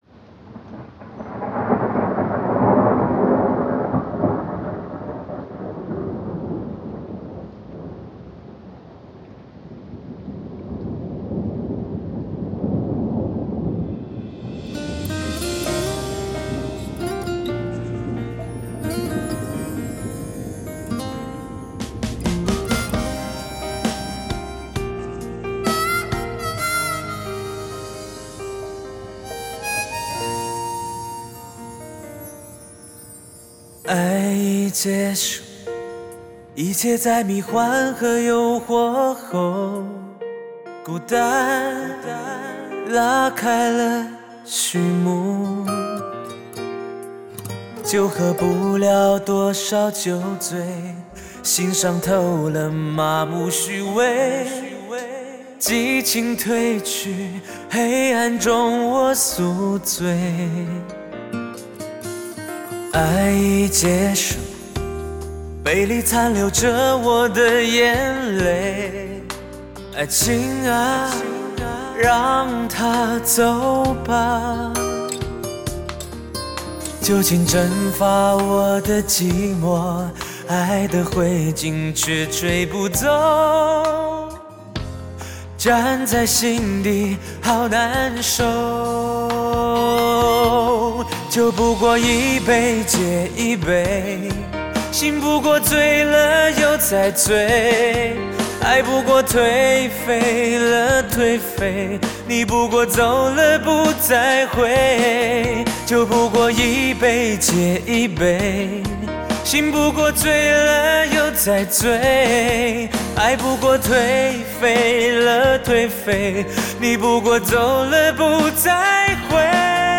DTS-5.1声道音乐